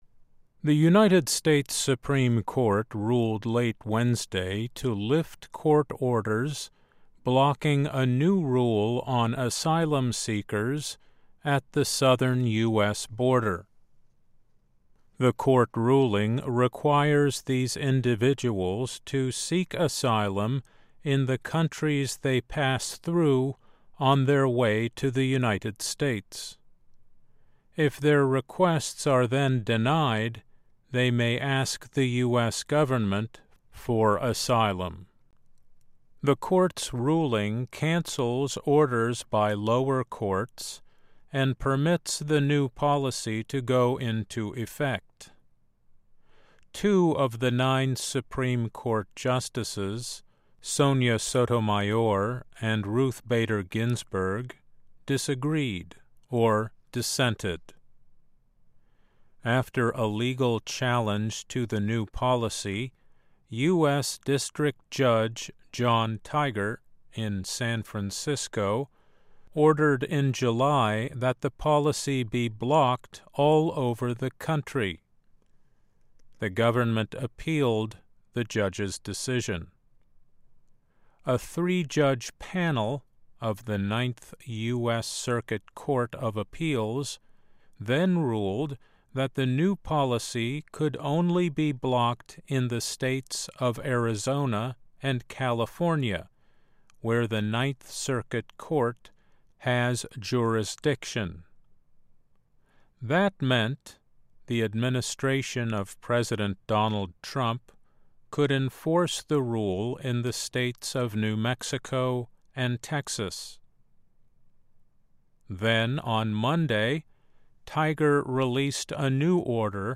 慢速英语:美国最高法院裁定新的庇护规则可以继续执行